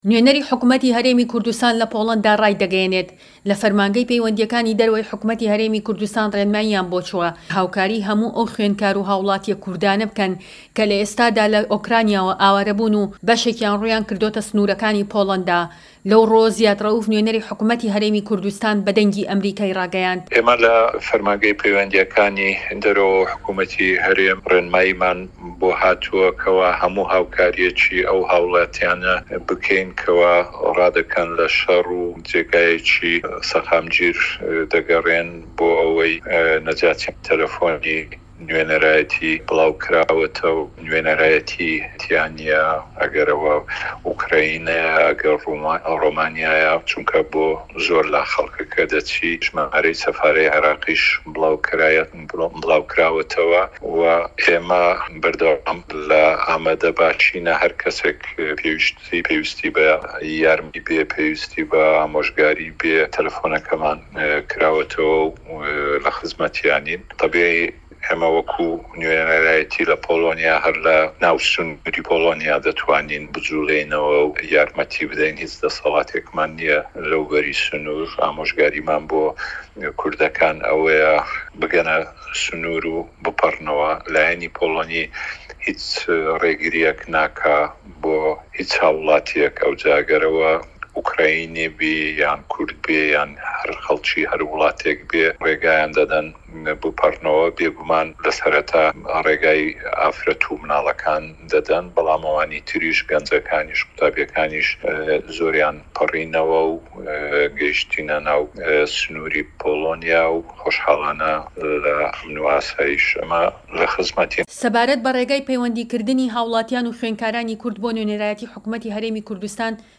وتووێژ لەگەڵ نوێنەری حکومەتی هەرێمی کوردستان لە پۆڵەندا